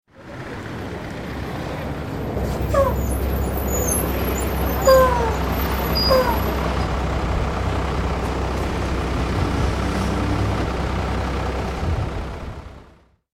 دانلود صدای اتوبوس 8 از ساعد نیوز با لینک مستقیم و کیفیت بالا
جلوه های صوتی